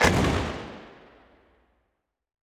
dunk.ogg